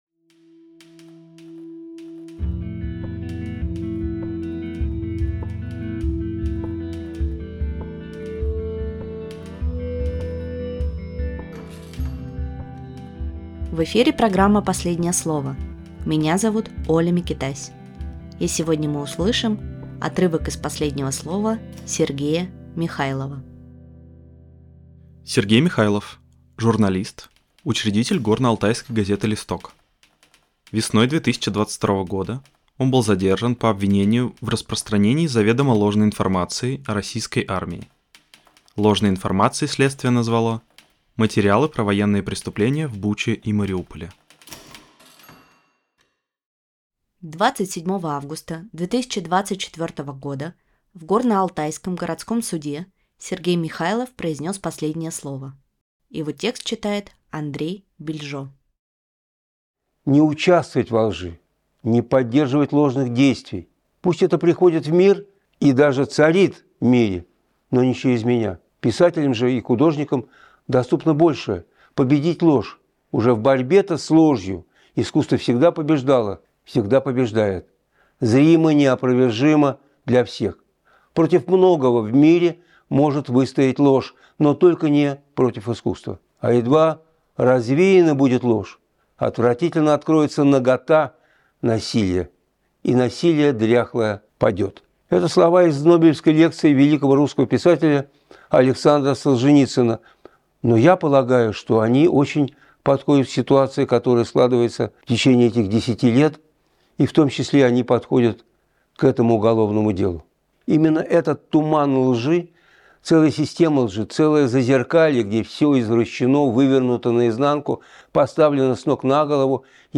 Читает Андрей Бильжо